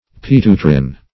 Search Result for " pituitrin" : The Collaborative International Dictionary of English v.0.48: Pituitrin \Pi*tu"i*trin\, n. (Biol.
pituitrin.mp3